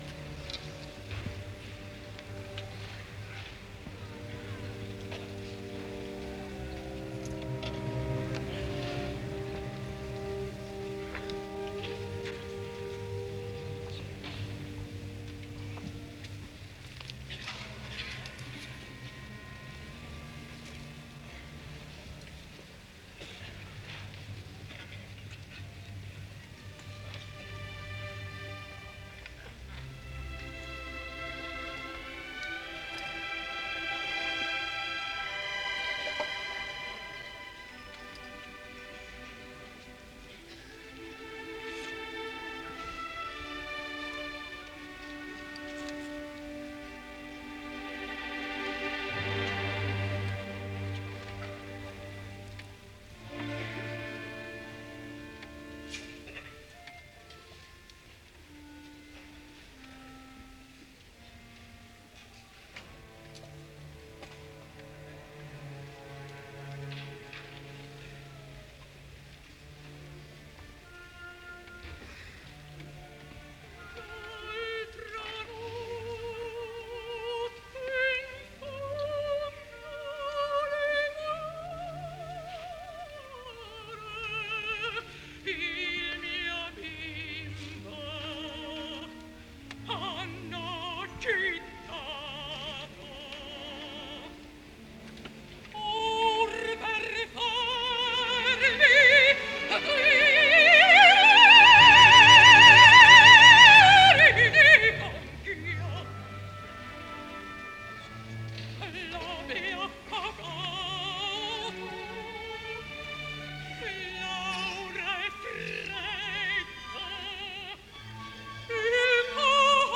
Жанр: Opera
итальянская оперная певица, сопрано.